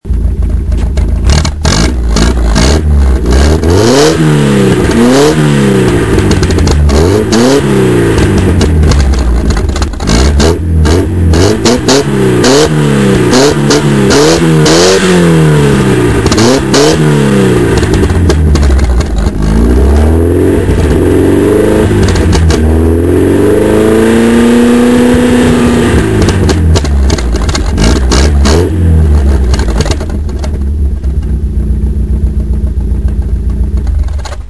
Ecoutez le petit décrassage moteur de cette Balocco (à n'effectuer qu'avec le moteur bien en température et les courroies de distribution en parfait état et correctement tendues).